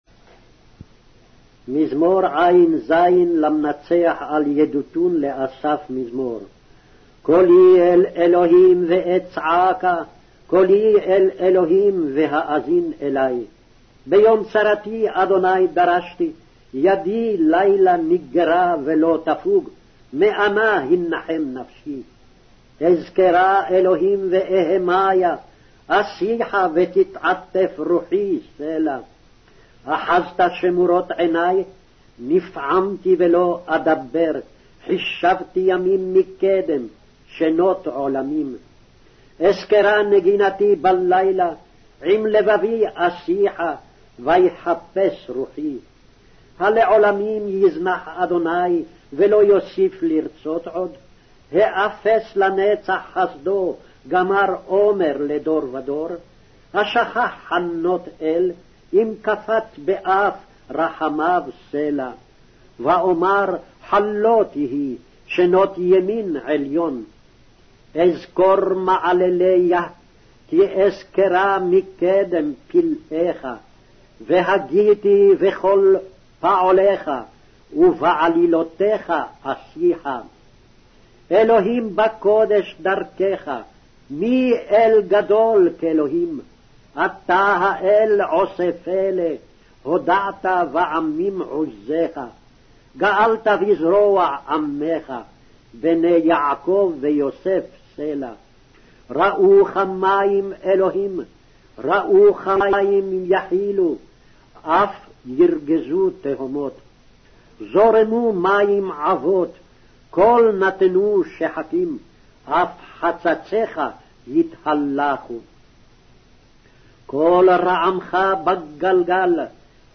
Hebrew Audio Bible - Psalms 90 in Knv bible version